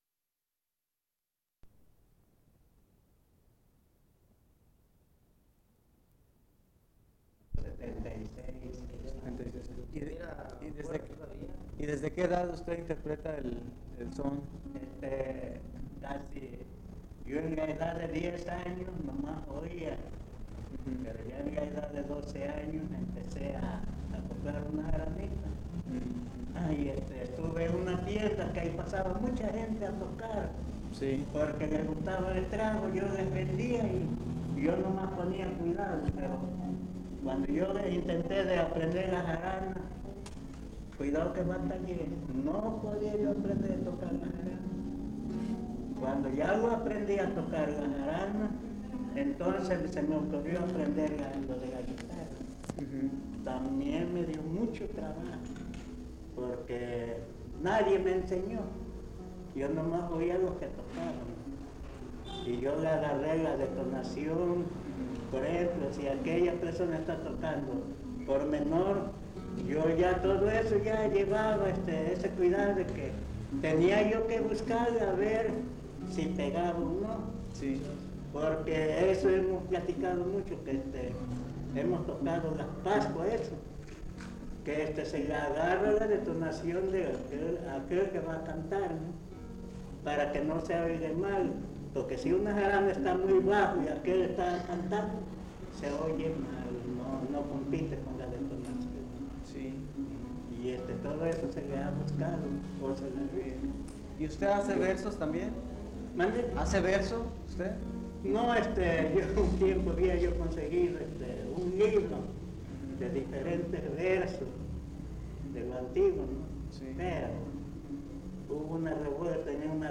Comoapan, San Andrés, Veracruz
Entrevista